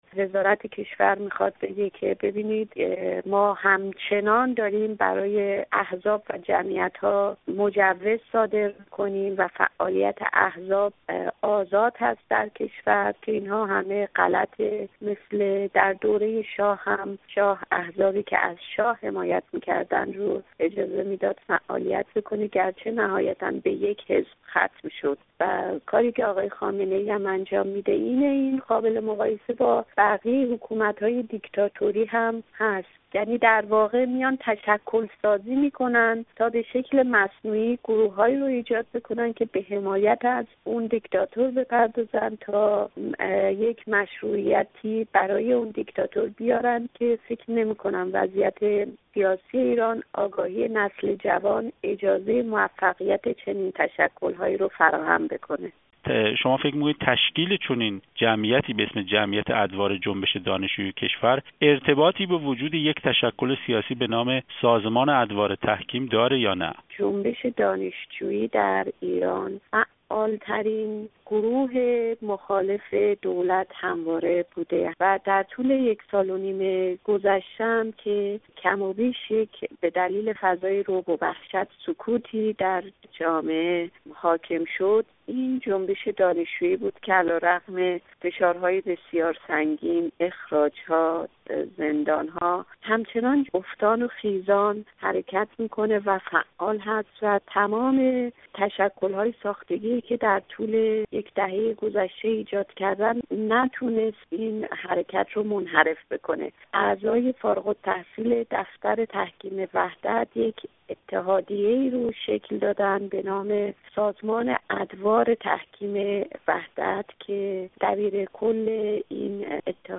گفت و گو با فاطمه حقیقت جو درباره صدور مجوزهای تازه برای تشکیل نهادهای تازه دانشجویی